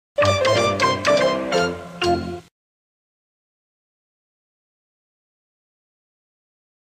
Play, download and share Stacco Pubblicitario original sound button!!!!
stacco-pubblicitario.mp3